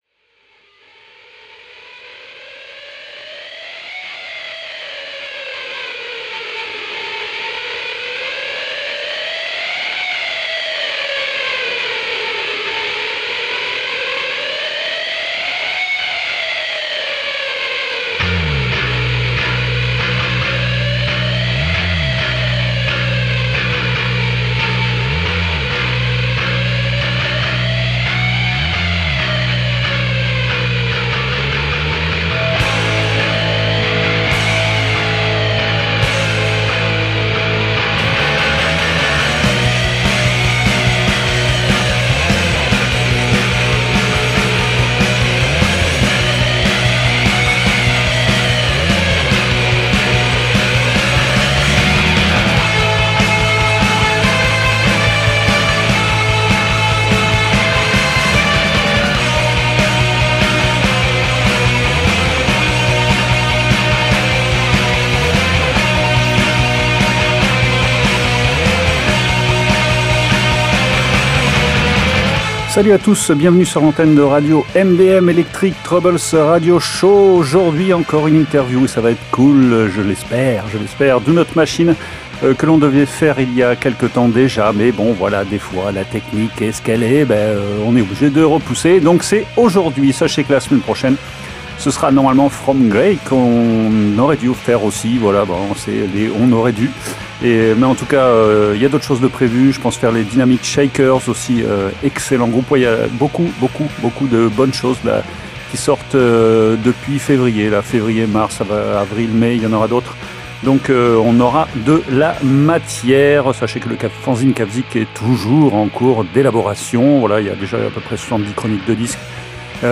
Ce nouvel album, c’est neuf chansons de « fuzz with melodies » comme aime le résumer le groupe, couplant post hardcore et atmosphères mélancoliques.